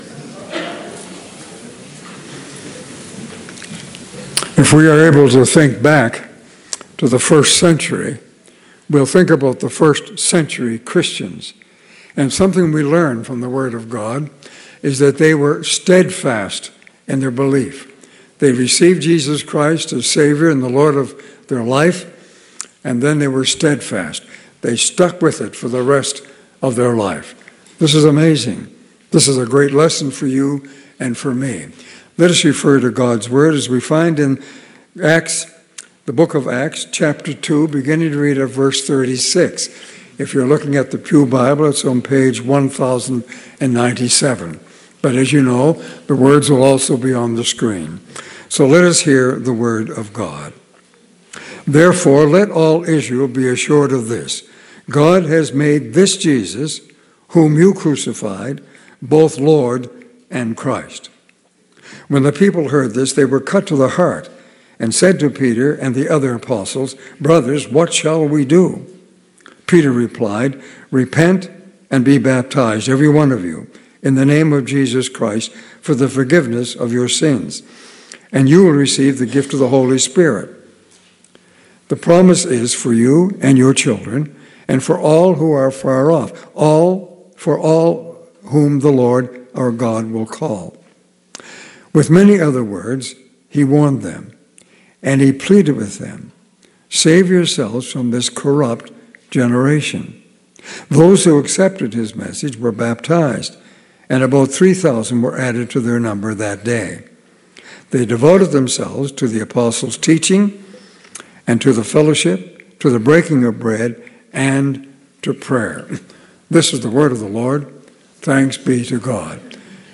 Audio Sermons - Campbellford Baptist Church Inc.